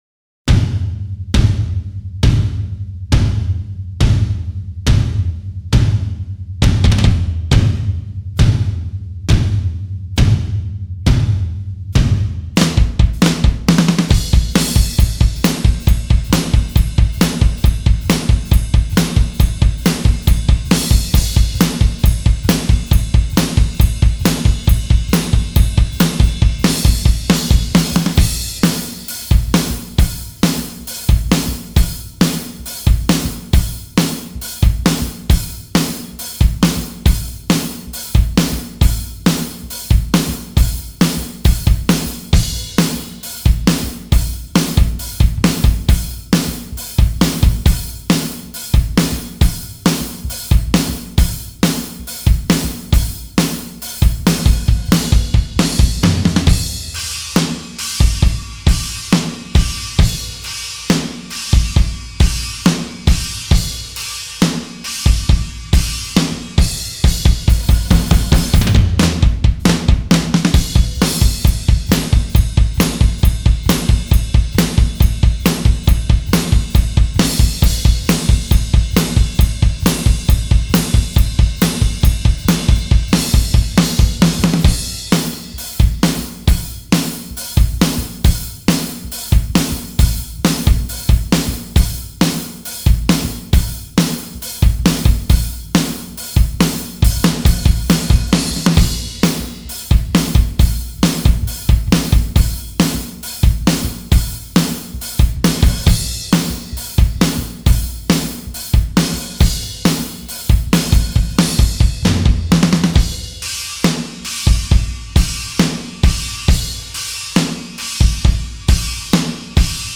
I track drums from start to finish without fixes.
Just Drums